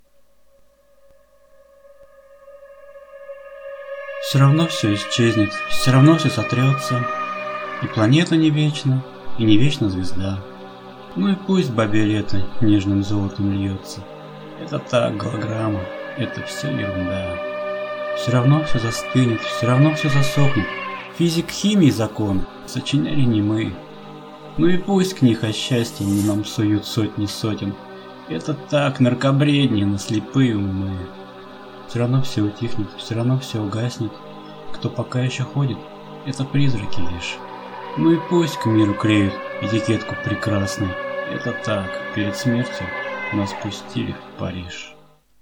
Прослушать в авторском исполнении: